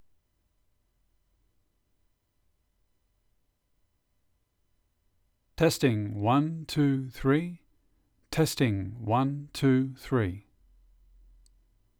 Another Blue Yeti Recording - Noise
I record from a shelf in my wardrobe which has pyramid foam on all sides except the top. The opposite wall behind me has a blanket over it.
The second sample is recorded with my PC turned off, so im using a Windows tablet.
Actually I would characterize the noise level in both of those recordings as “not bad”.
There is a little bit of USB whine and the rest sounds like thermal noise from the mic preamp.
There is a lot of noise below about 120Hz which seems to be a common failing with USB microphones.